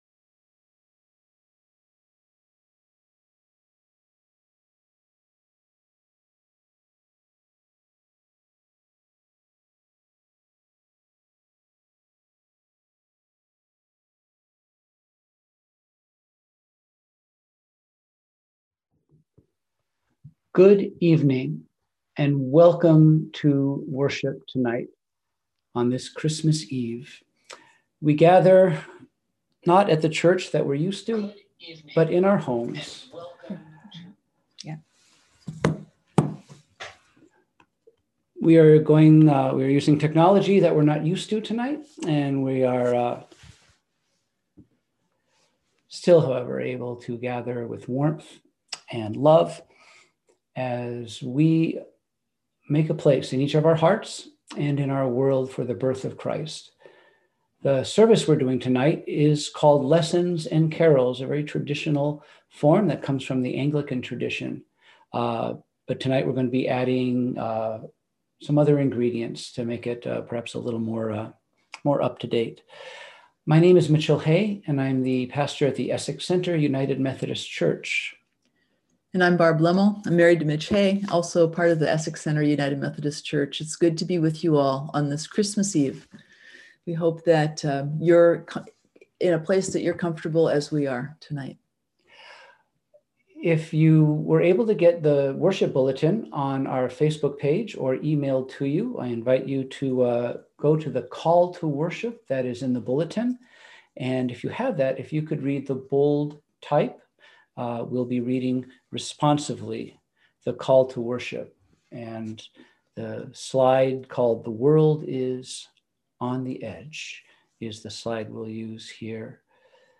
We held our Christmas Eve service of Lessons and Carols on Thursday, December 24, 2020 at 7:00pm!
Christmas Eve – Lessons and Carols
christmas-eve-virtual-worship.mp3